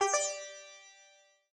sitar_gd1.ogg